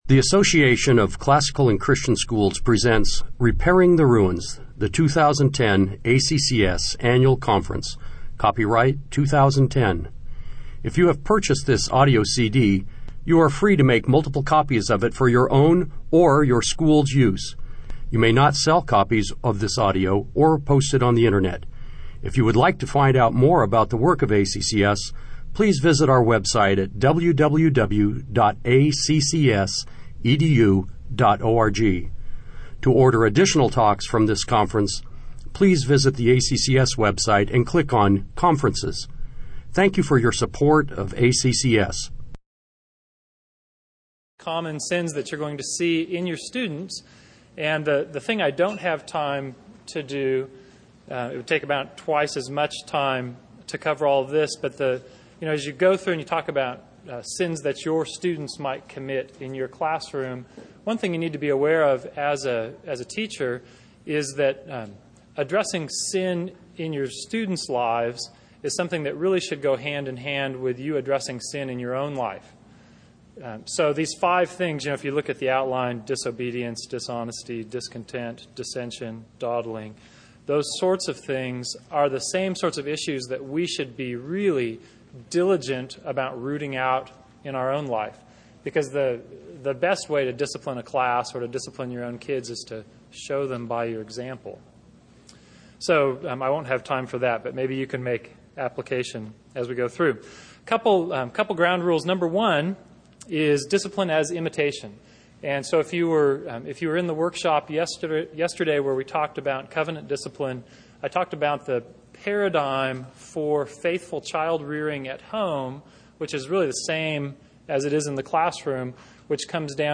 2010 Workshop Talk | 1:02:44 | All Grade Levels, General Classroom, Virtue, Character, Discipline